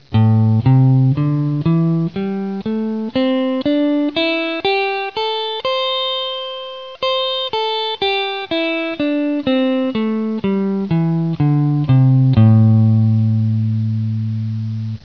סולם פנטטוני
זהו סולם Am (פנטטוני מינורי, מתחיל במיתר הנמוך בסריג מספר 5).